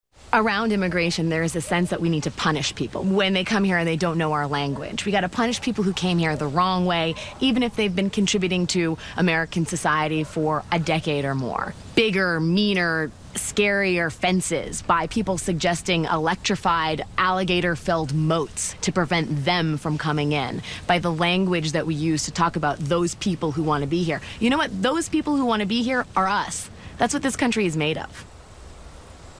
This time, liberal anchor Alex Wagner slammed anyone who wants border security, sneering that they want "bigger, meaner, scarier fences" and "electrified, alligator moats to prevent 'them' coming in."
Alex Wagner Lean Forward ad